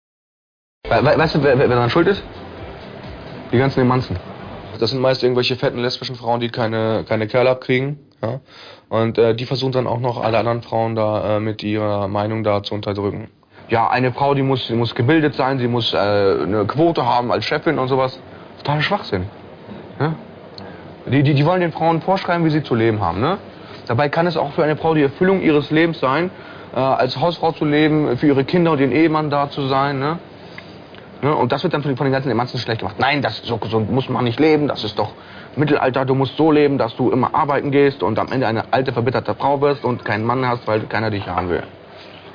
Der Originalton eines jungen Mannes aus dem Genre Reality TV/Doku-Soap.